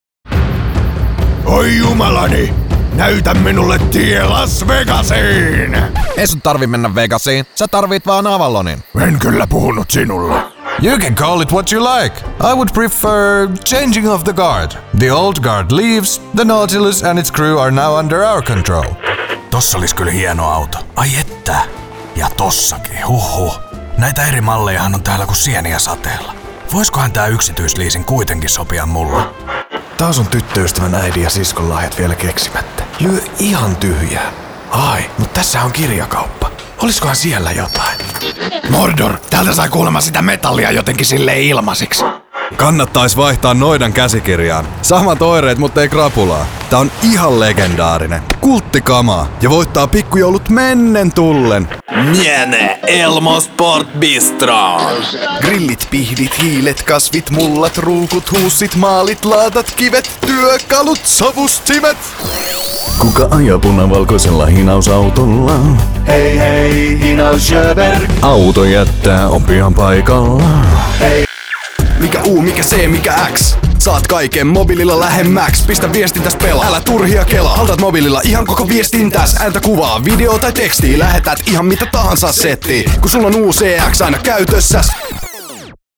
Junge, Cool, Corporate
Persönlichkeiten